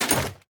equip_netherite2.ogg